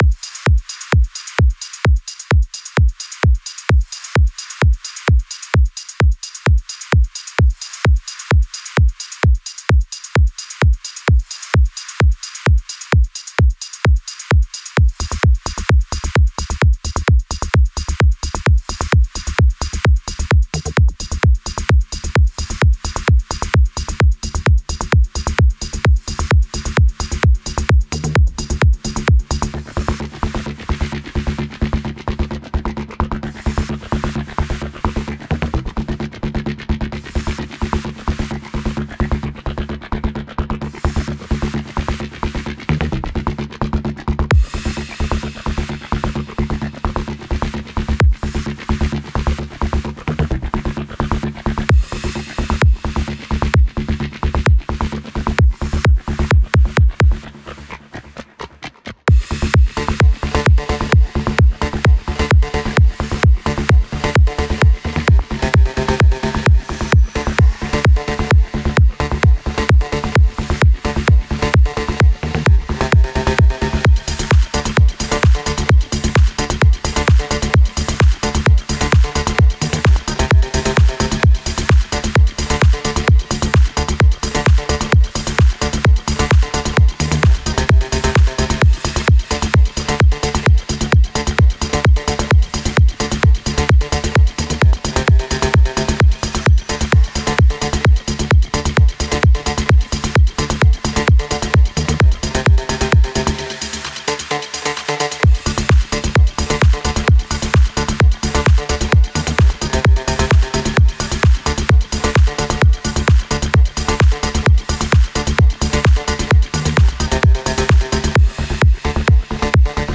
Type: Midi Templates Samples
Deep House Tech House Trance